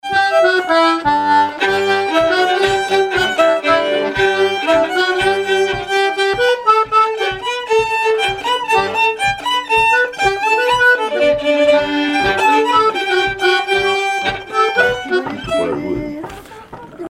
Saint-Pierre-et-Miquelon
Genre laisse
Pièce musicale inédite